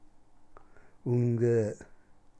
スピーカうんげぇ˚ー/ うんげぇー〈恩義は〉（多良間方言）